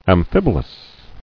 [am·phib·o·lous]